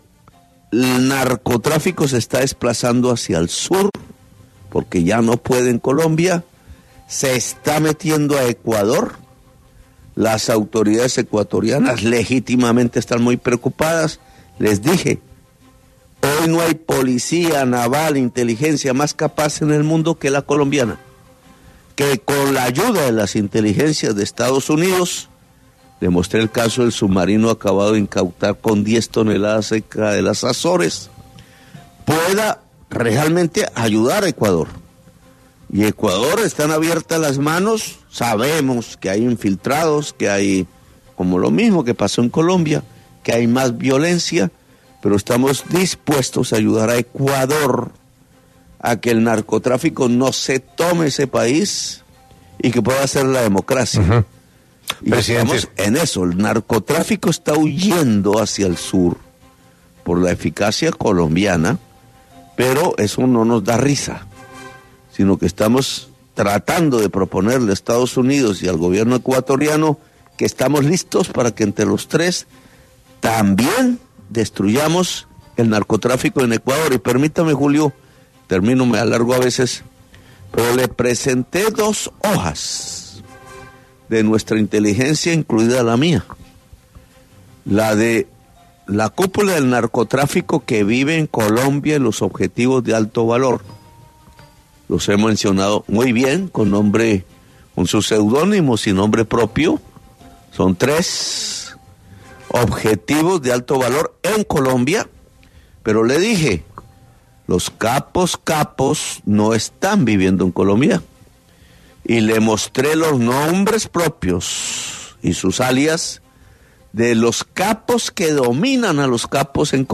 Gustavo Petro, presidente de Colombia, habló en exclusiva para los micrófonos de Caracol Radio, con Julio Sánchez Cristo, para referirse a la reunión que sostuvo con Donald Trump.